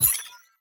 Hi Tech Alert 9.wav